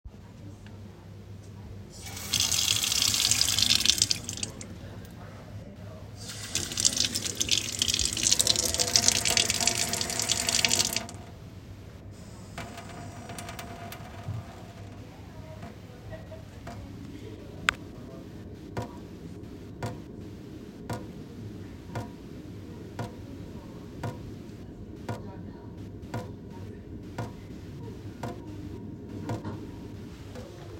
waterfaucetsoundsdropping.m4a